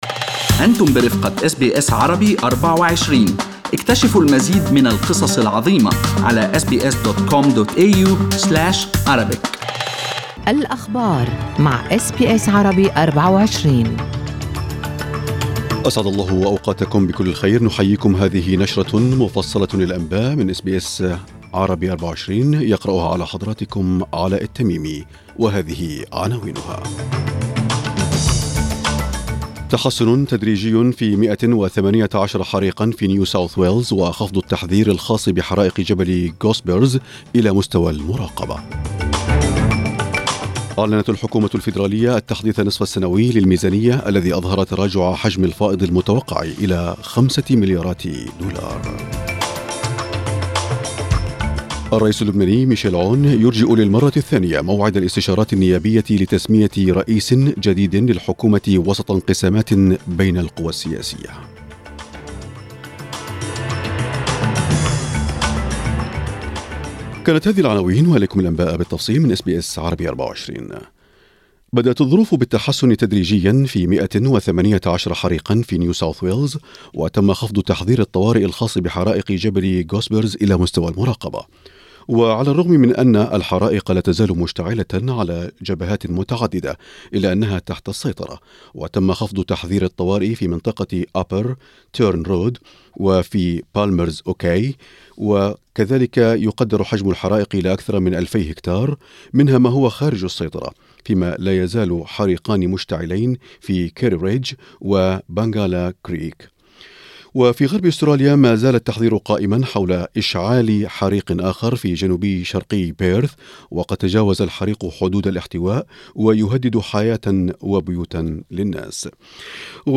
أخبار الصباح: الموجة الحارة تبدأ اليوم ورجال الإطفاء يسابقون الزمن لاحتواء الحرائق